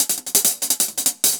UHH_AcoustiHatC_170-05.wav